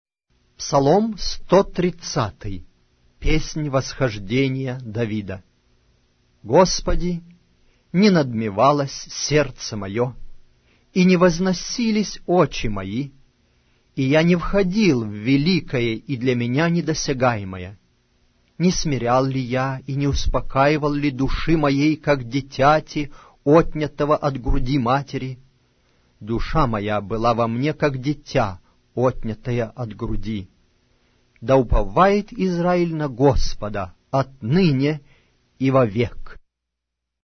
Аудиокнига: Псалтирь